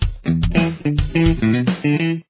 Guitar 1